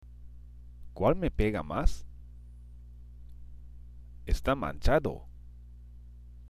＜発音と日本語＞